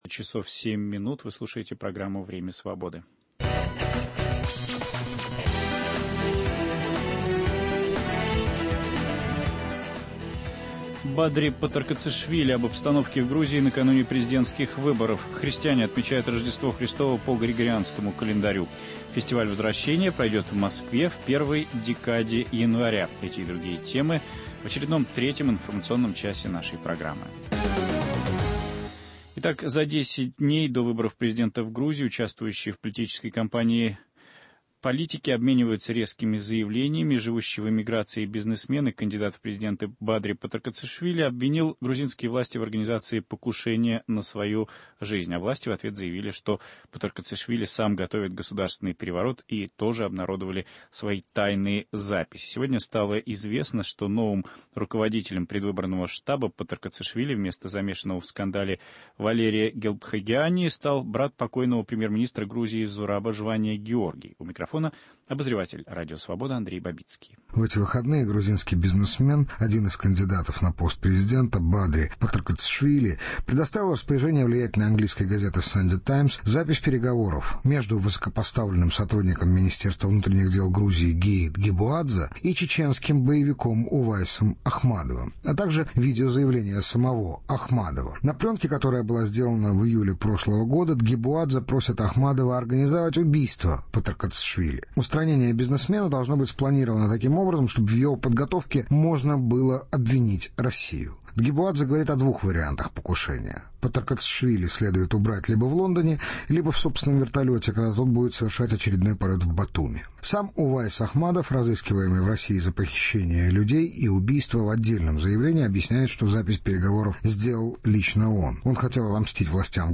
Интервью Андрея Бабицкого с Бадри Патаркацишвили